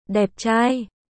Đẹp trai（デップチャーイ）：